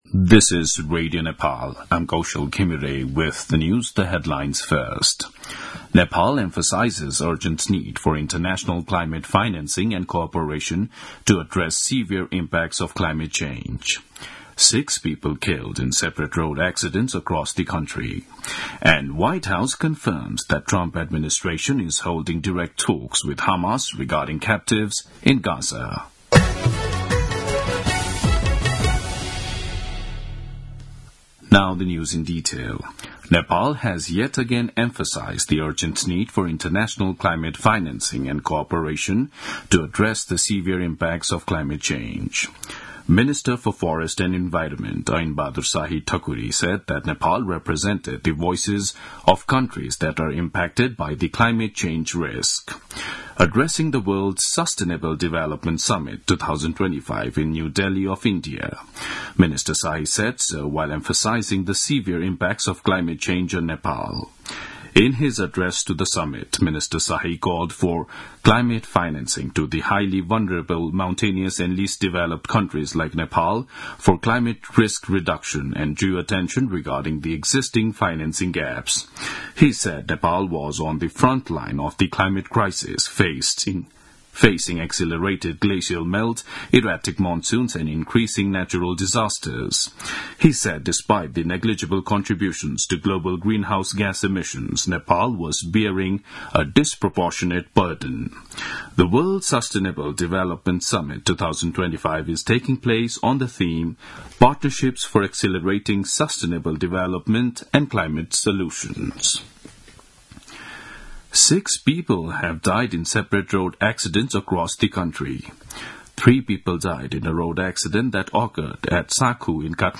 दिउँसो २ बजेको अङ्ग्रेजी समाचार : २४ फागुन , २०८१
2-pm-news-1.mp3